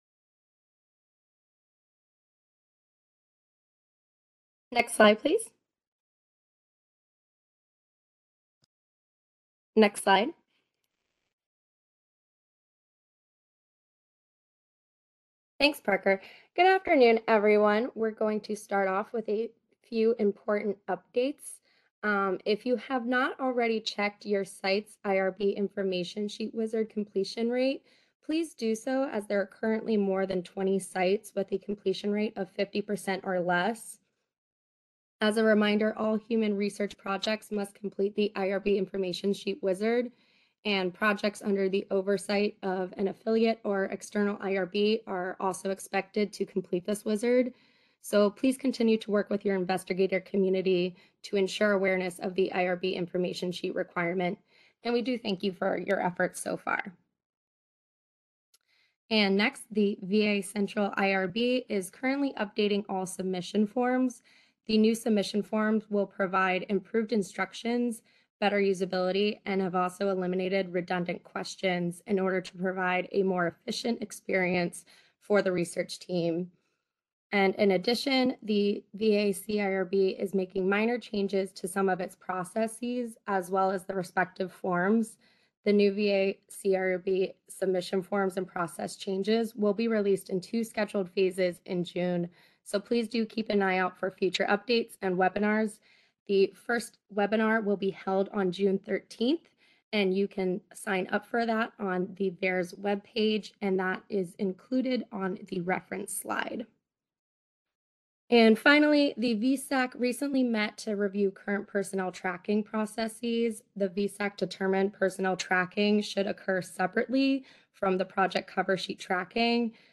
VAIRRS Monthly Webinar Series: Data Availability